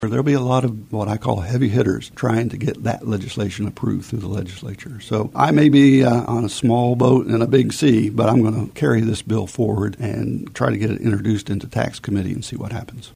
Tom Phillips, a Kansas House of Representative member representing the 67th district, appeared on today’s episode of In Focus to preview some of what he will be working on in the upcoming legislative session in the areas of healthcare, higher education and “dark store theory.”